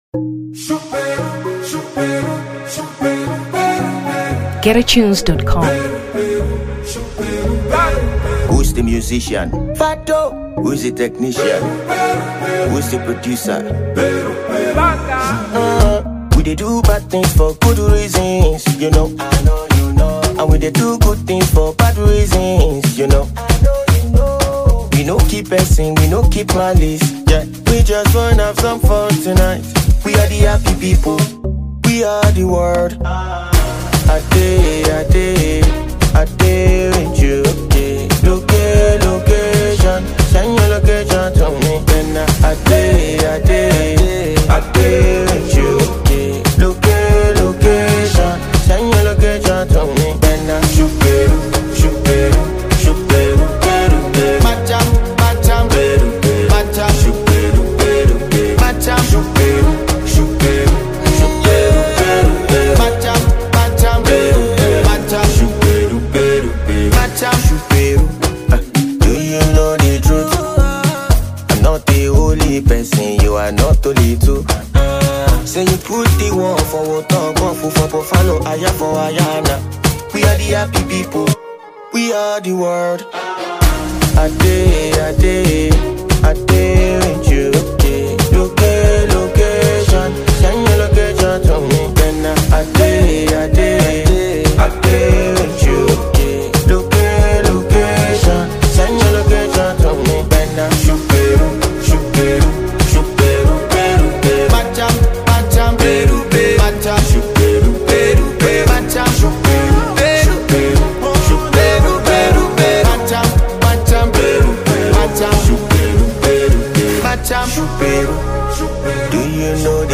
Afrobeats 2023 Nigeria